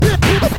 Free MP3 scratches sound effects 10